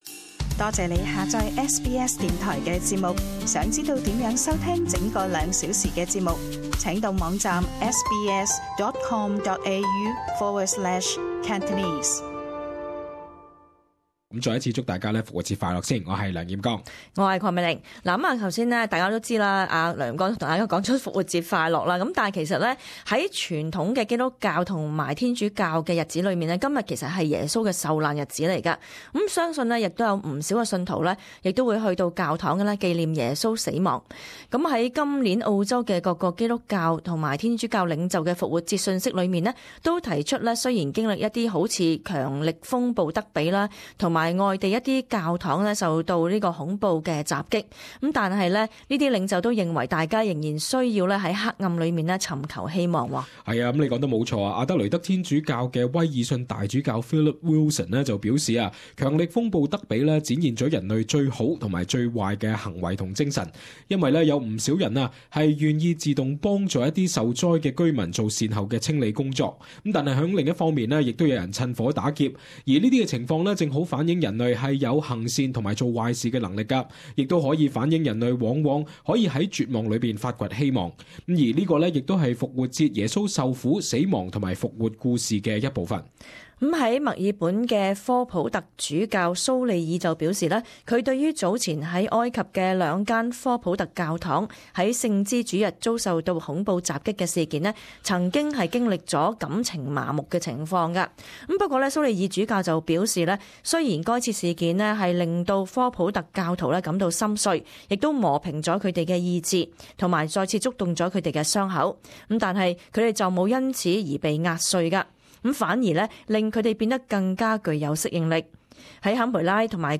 【時事報導】復活節宗教領袖訊息